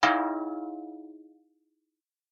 Thin bell ding 4
bell chime ding dong short sound effect free sound royalty free Sound Effects